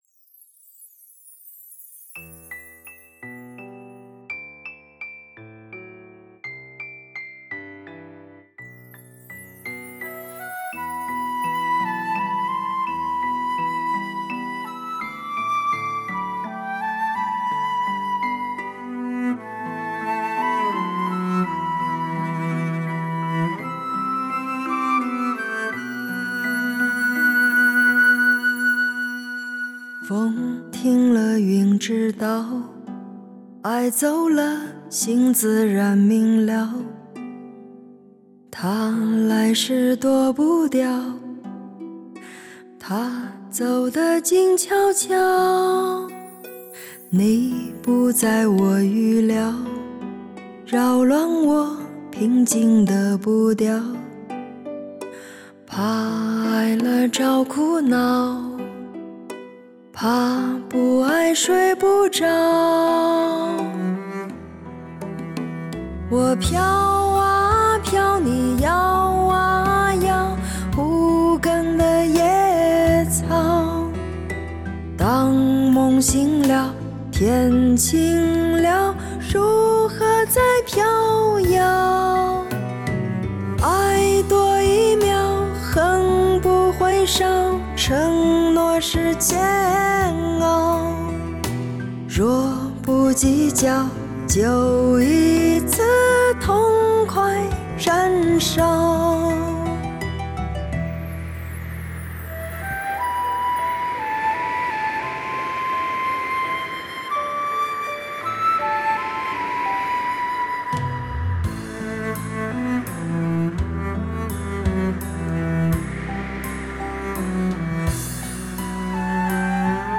用纯如乙醚的声线，燃点沉香般的心事。